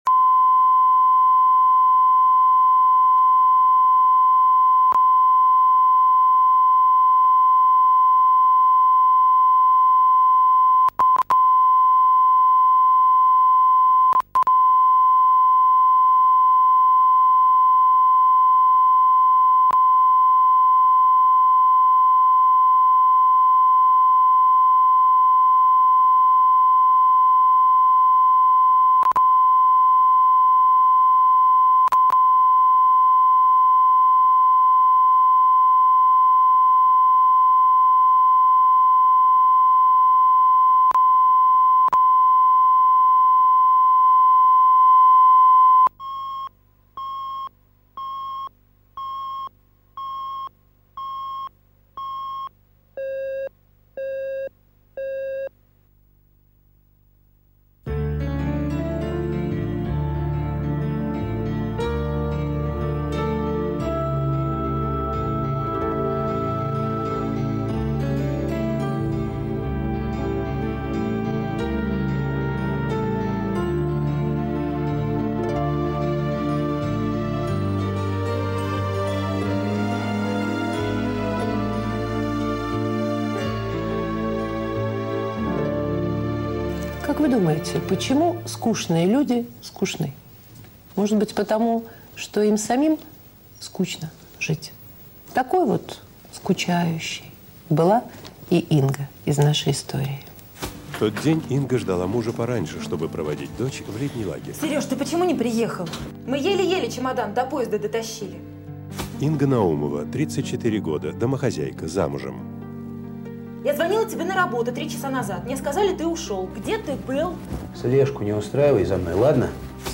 Аудиокнига Колье